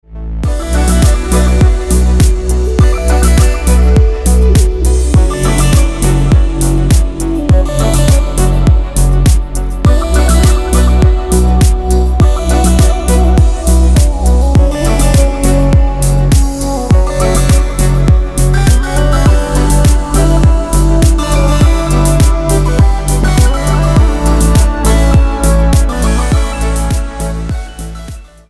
без слов # спокойные # тихие